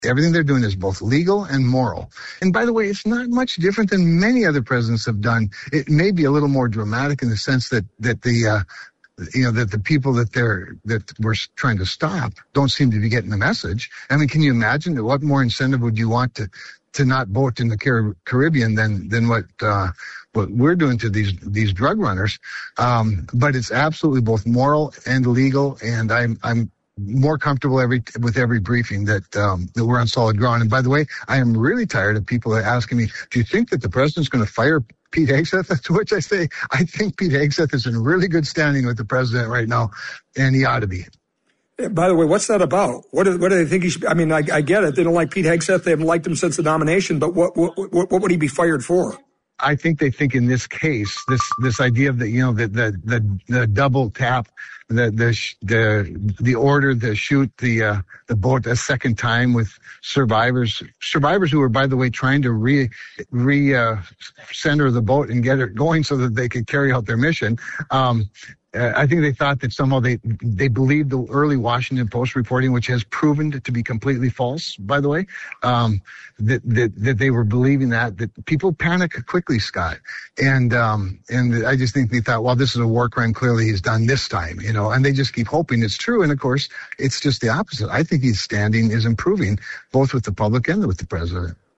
Listen: North Dakota Senator Kevin Cramer on The Flag.
cramer-on-boat-strikes.mp3